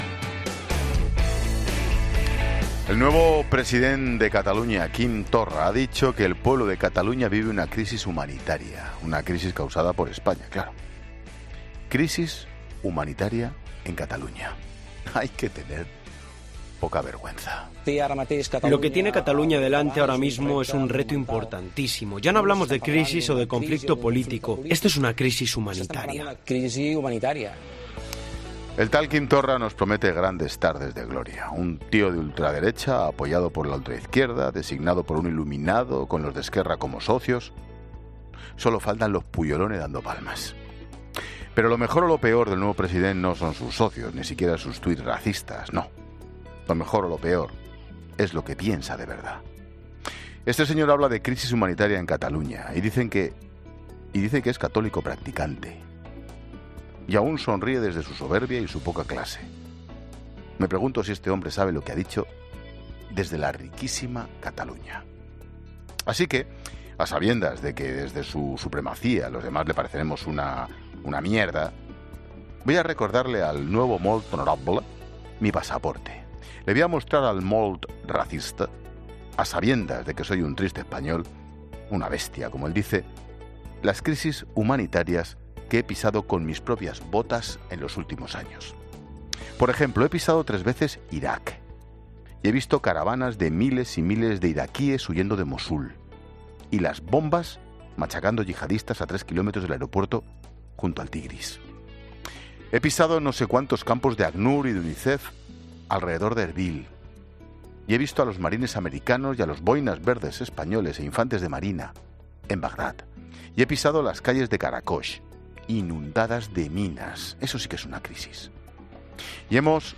Monólogo de Expósito
El comentario de Ángel Expósito sobre el nuevo presidente de Cataluña.